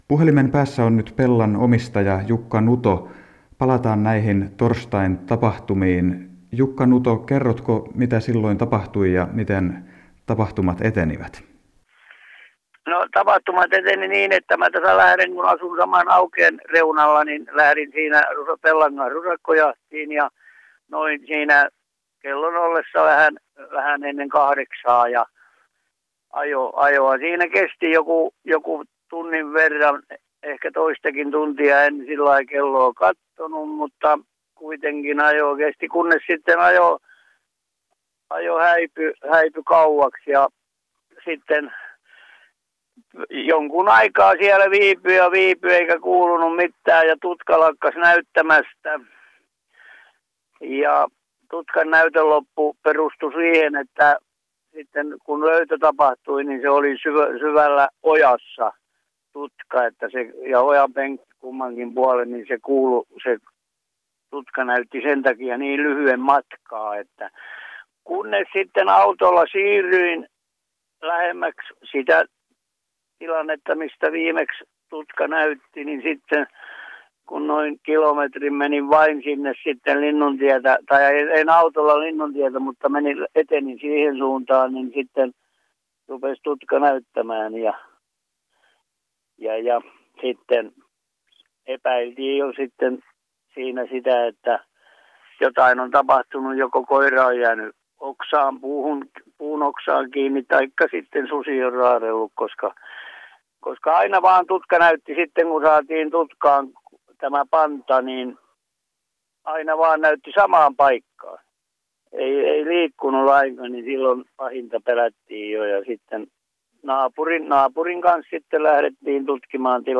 haastateltavina